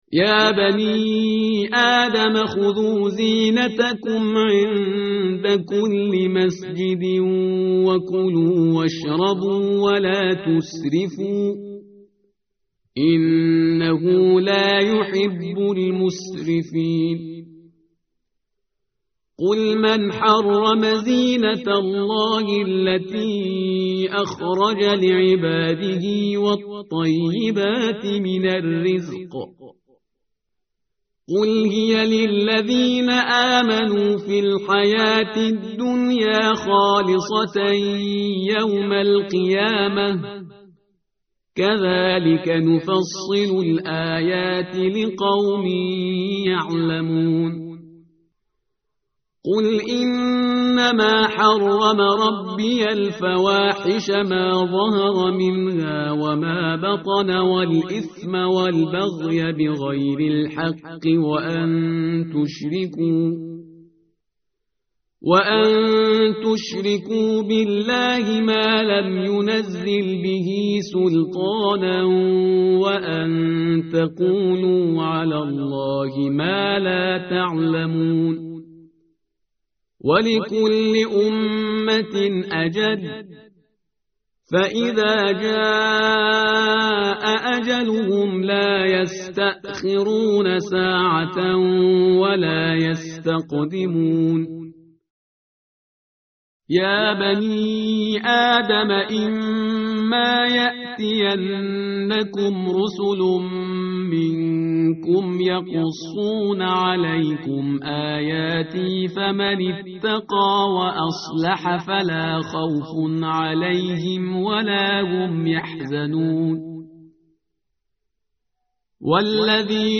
متن قرآن همراه باتلاوت قرآن و ترجمه
tartil_parhizgar_page_154.mp3